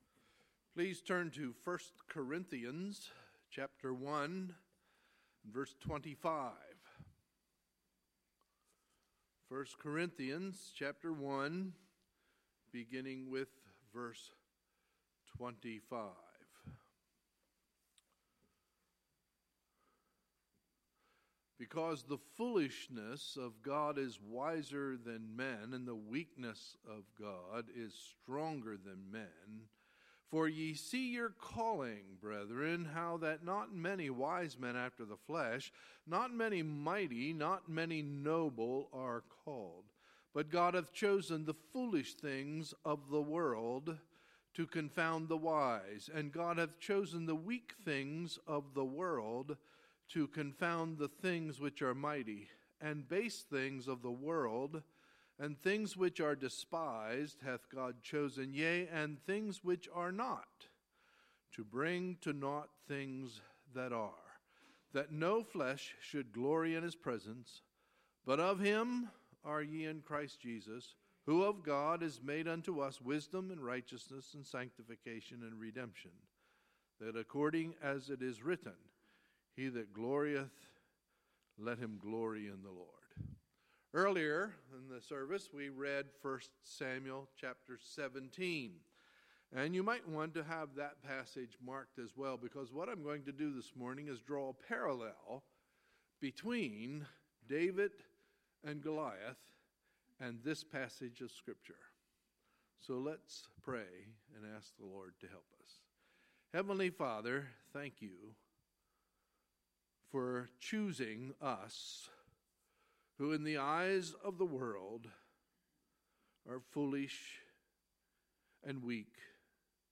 Sunday, January 29, 2017 – Sunday Morning Service